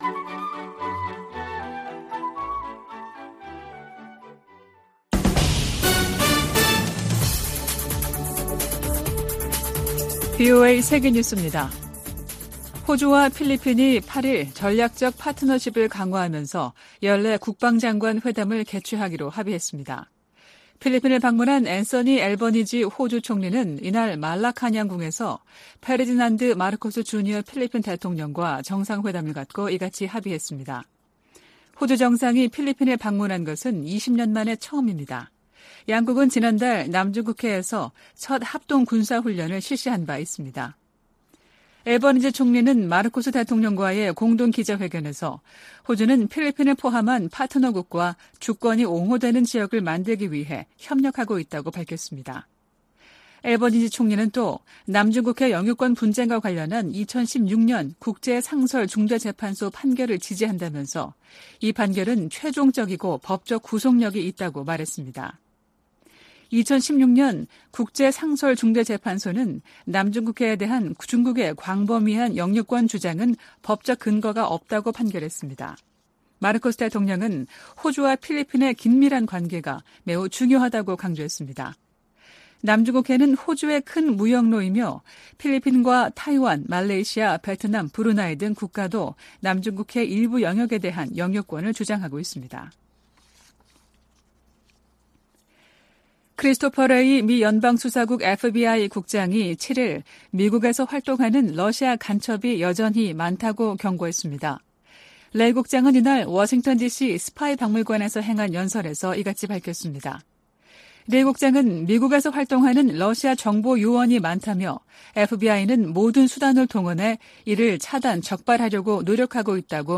VOA 한국어 아침 뉴스 프로그램 '워싱턴 뉴스 광장' 2023년 9월 9일 방송입니다. 북한이 수중에서 핵 공격이 가능한 첫 전술 핵공격 잠수함인 '김군옥 영웅함'을 건조했다고 밝혔습니다. 인도네시아에서 열린 동아시아정상회의(EAS)에 참석한 카멀라 해리스 미국 부통령이 북한의 위협적 행동을 강력히 규탄했습니다. 러시아와 무기 거래를 하려는 북한은 '매우 위험한 게임'을 하는 것이라고 미국 상원 외교위원장이 지적했습니다.